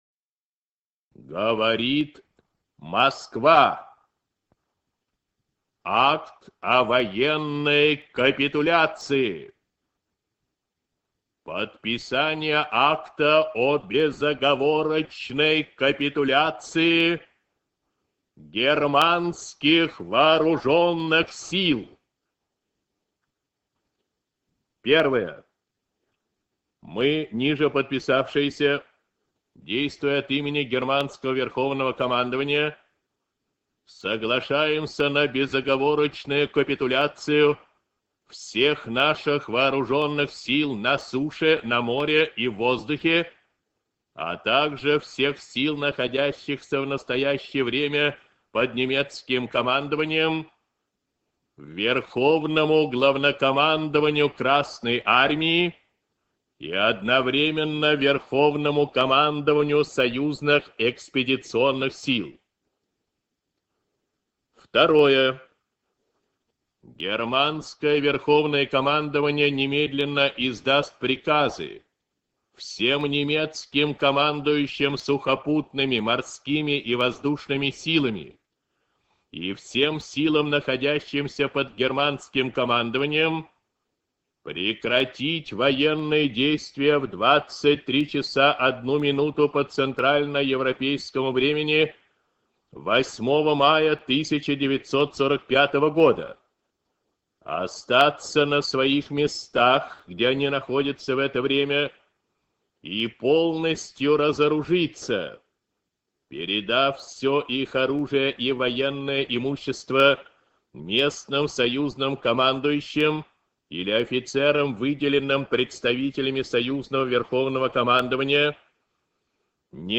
Сообщение советского радио о капитуляции Германии. Текст читает Ю. Левитан